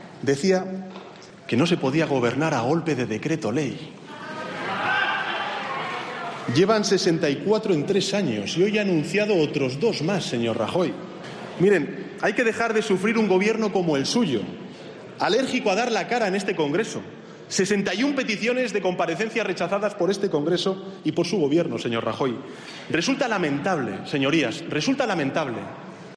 Sánchez acusando a Rajoy de abusar del decreto ley en el Debate sobre el estado de la Nación en 2015